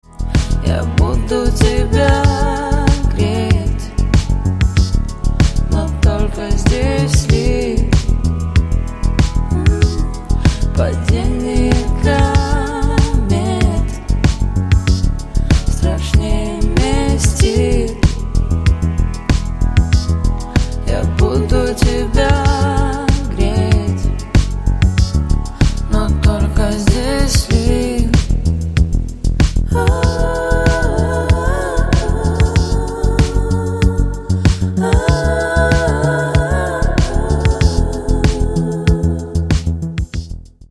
Спокойные И Тихие Рингтоны
Поп Рингтоны